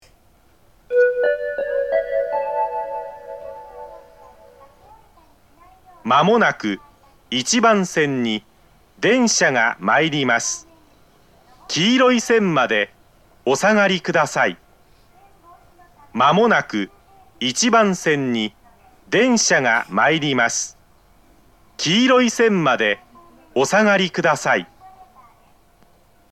（男性）
接近放送
巌根型の接近放送です。男性の放送です。